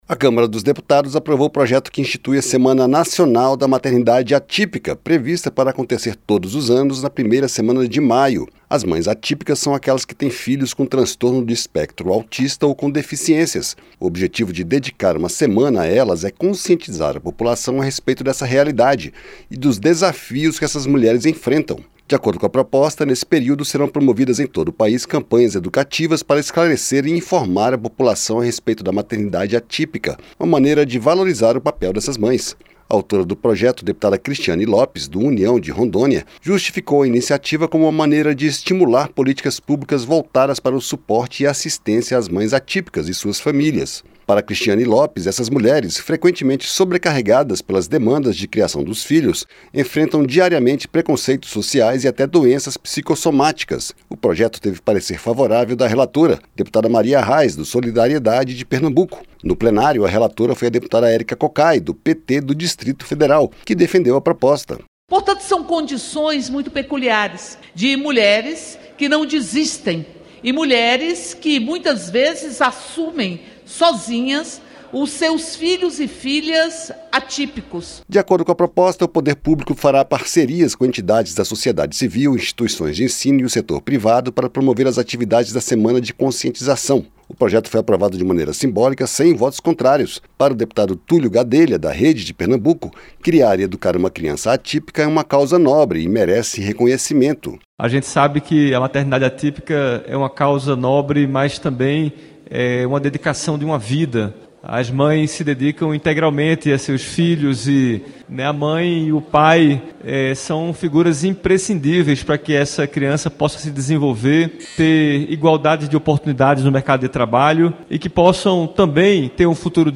Plenário aprova projeto que cria semana dedicada às mães de crianças com autismo ou deficiências - Radioagência - Portal da Câmara dos Deputados